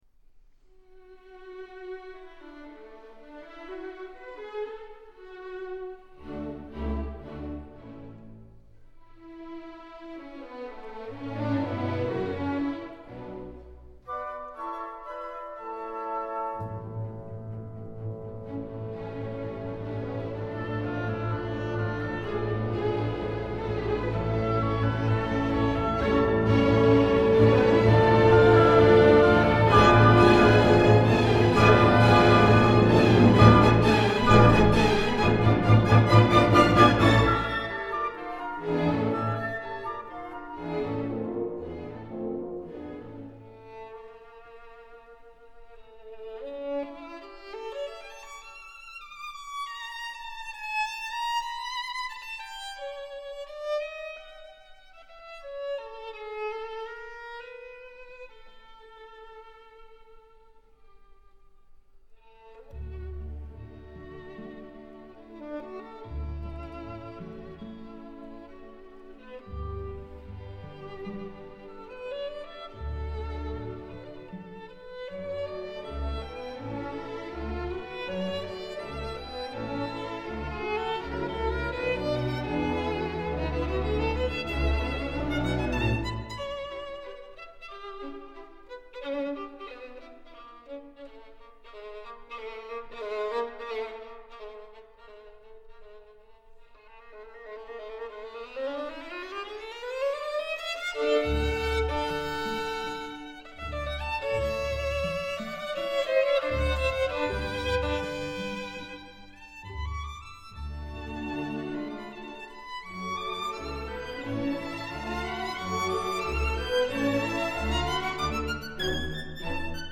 这部小提琴协奏曲是一部欢快、活泼、充满青春气息的作品，它歌唱青春，歌唱生命，表现了俄罗斯人民的乐观主义精神。